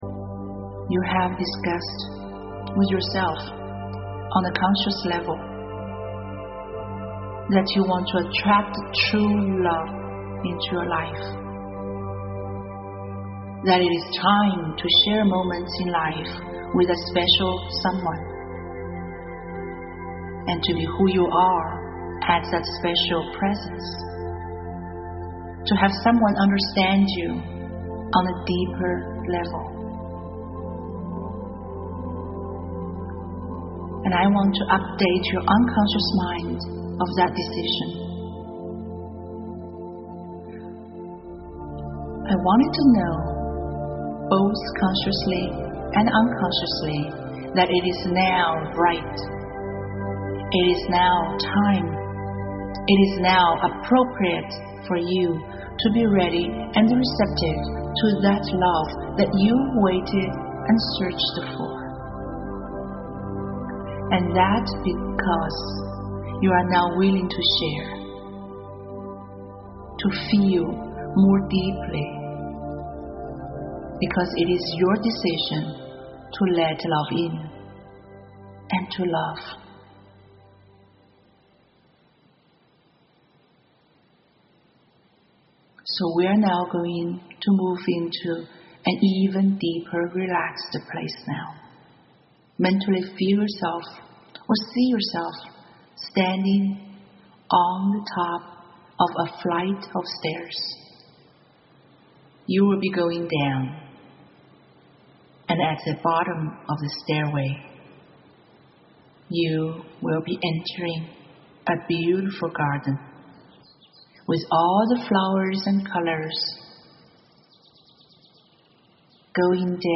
A high-quality production of a guided journey into the beautiful garden of your mind and meet her – your true love, this hypnosis home session strengthens your self-image, nurtures your mind and soul, and offers you a chance to look into a near future, where you get insights into everything about her.